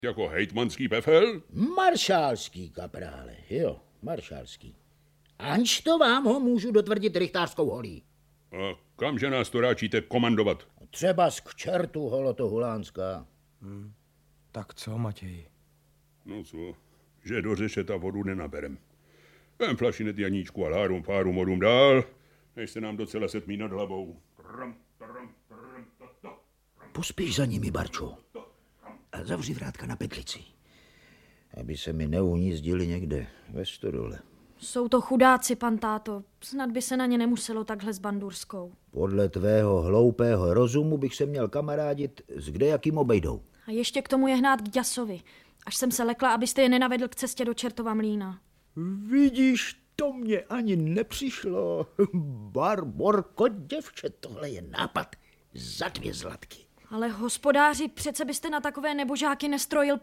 Audiobook
Read: Martina Hudečková